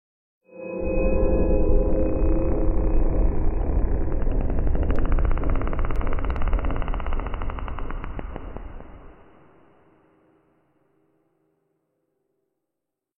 Minecraft Cave Sound 19
minecraft-cave-sound-19.mp3